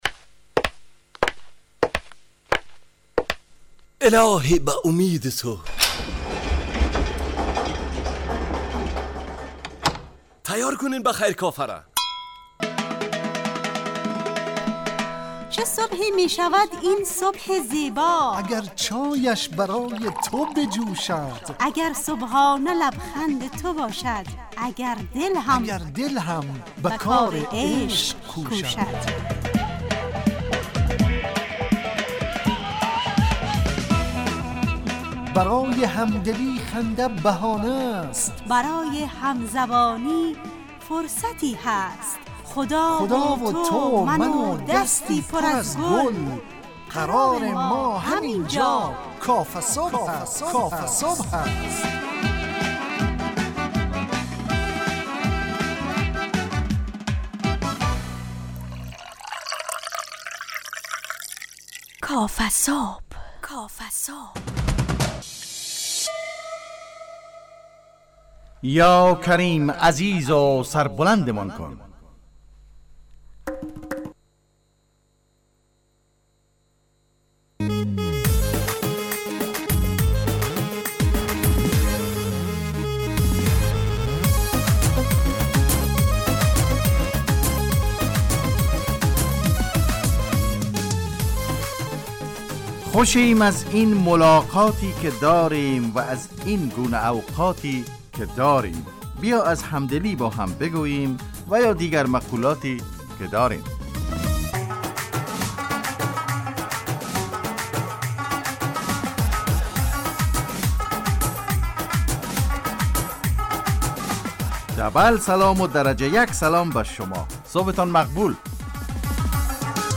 کافه‌صبح – مجله‌ی صبحگاهی رادیو دری با هدف ایجاد فضای شاد و پرنشاط صبحگاهی